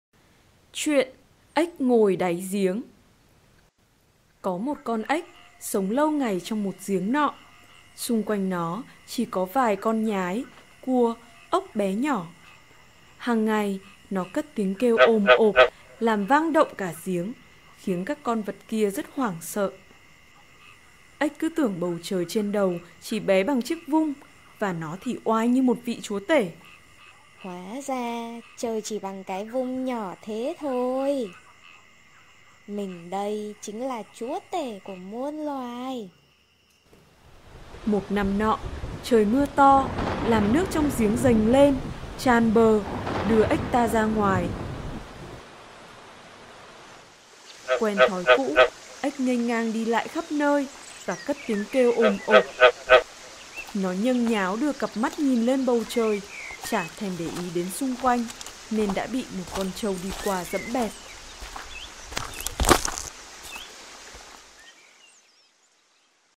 Sách nói | Ếch ngồi đáy giếng - Ngữ văn 7